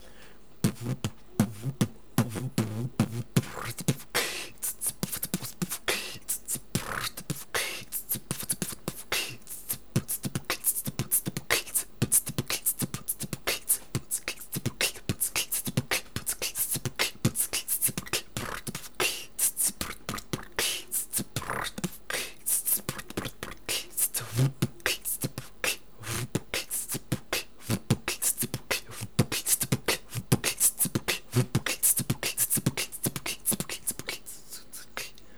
Форум российского битбокс портала » Реорганизация форума - РЕСТАВРАЦИЯ » Выкладываем видео / аудио с битбоксом » первый бит
bWB bWb bwbwbwb brr t t pf kch t t pf t pf t pf t kch t б t t б kch t t б t t б kch t (x2) б t kch t t б kch (x3) brr t t pf kch t t brr brr brr kch t ( x 2 ) wb kch t t б kch (x 6 ) t t б kch t t ........